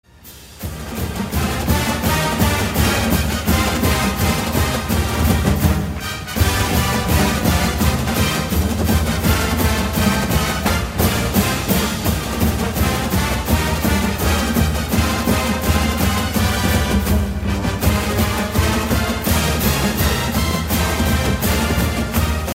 Fight Song